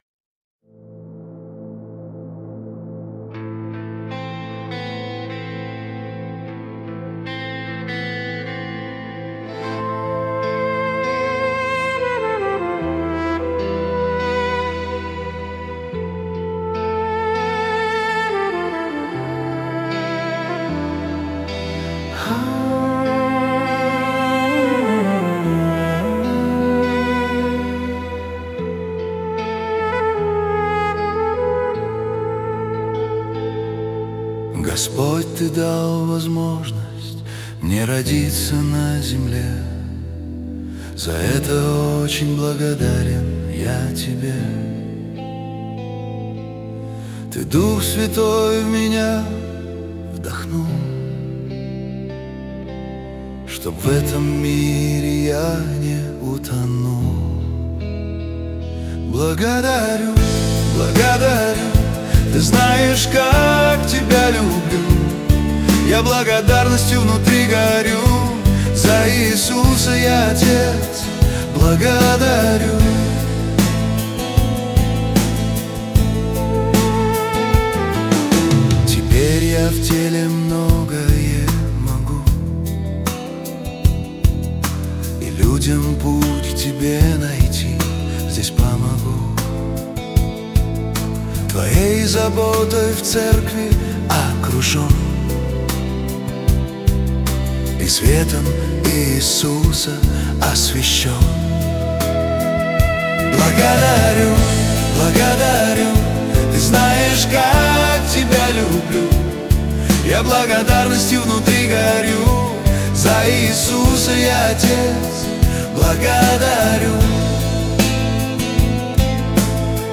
песня ai
243 просмотра 894 прослушивания 101 скачиваний BPM: 76